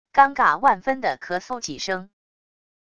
尴尬万分的咳嗽几声wav音频